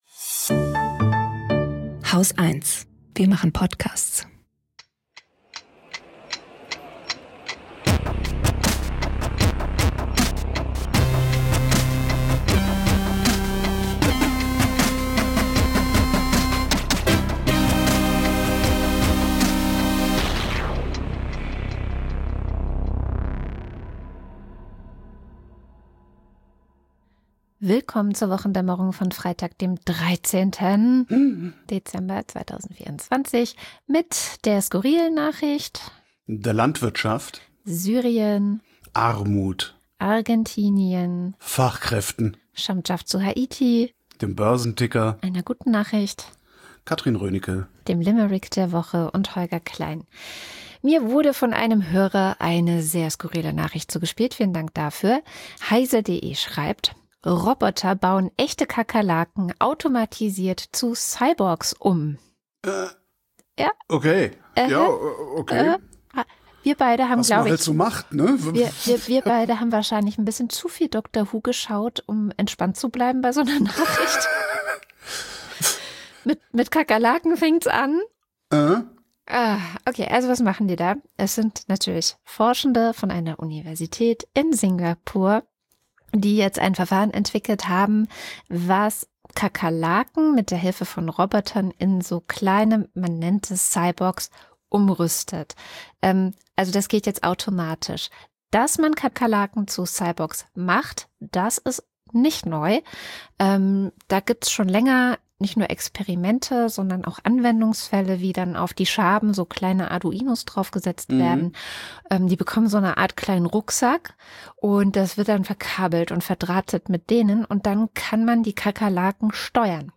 Gesprächspodcast
News Talk